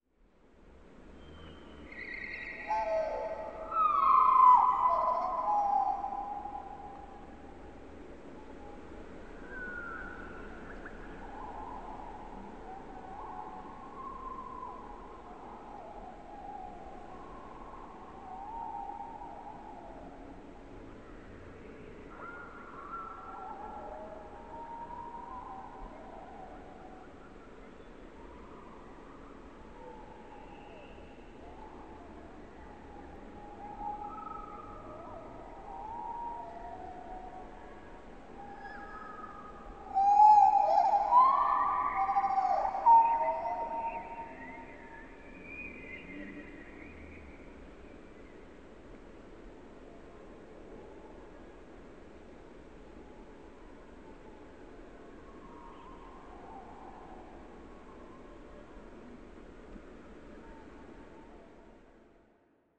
Лес в чуждом мире nЛес на далекой планете nЧужеродный лес nИнопланетный лес